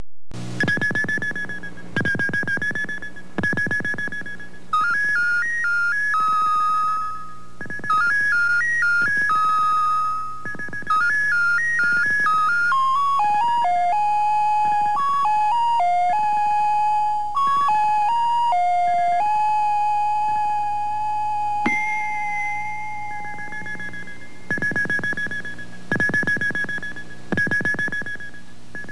theme song